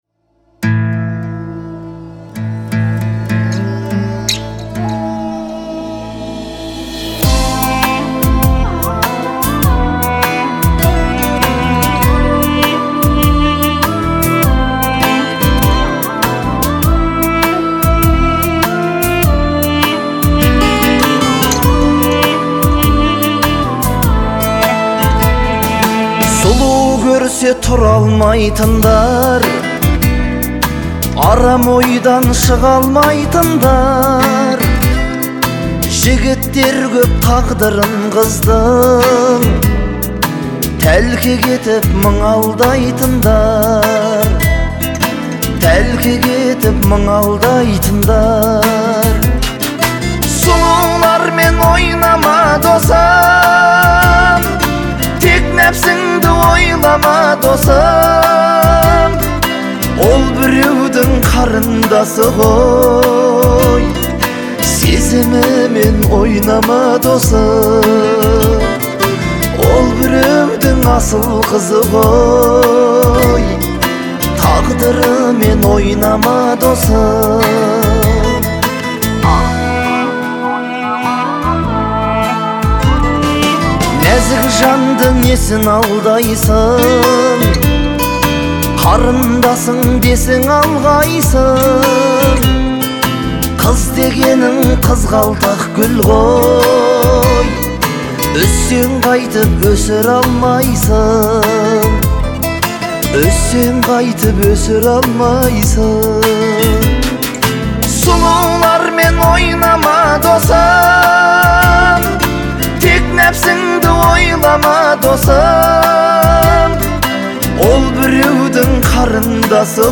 представляет собой трогательную балладу в жанре поп.